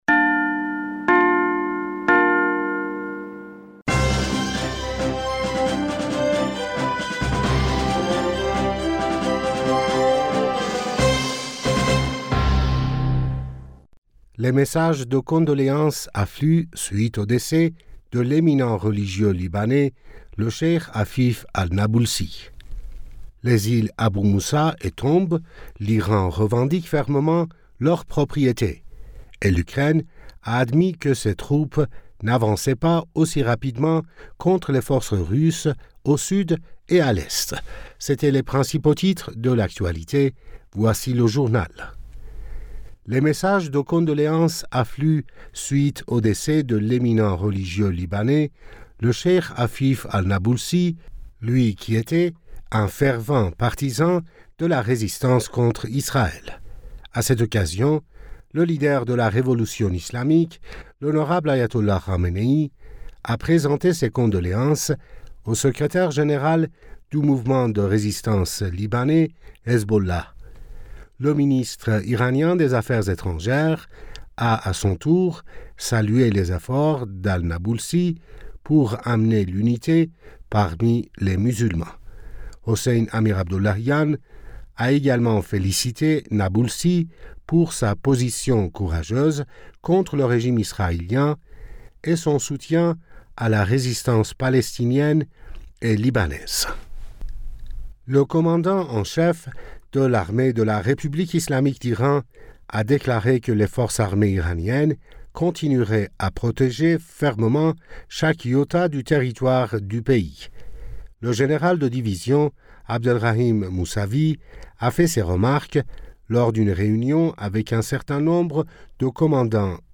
Bulletin d'information du 15 Juillet 2023